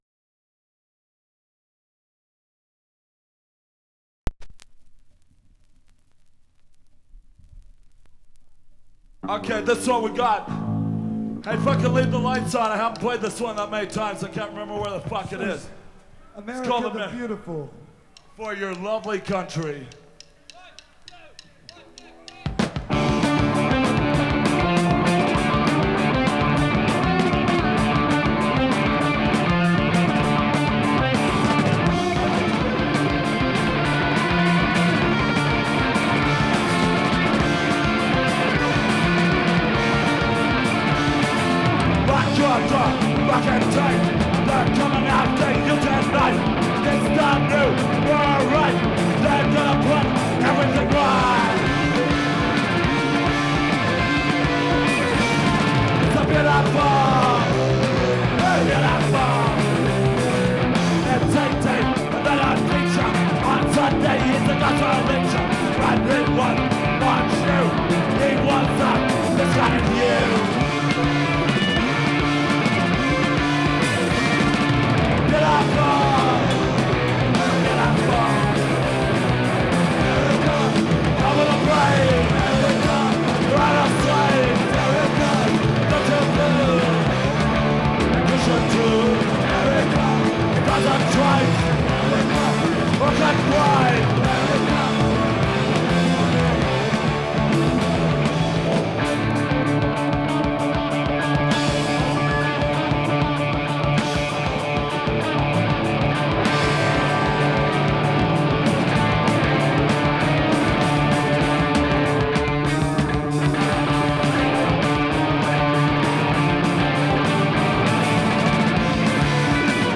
And it was a live album*.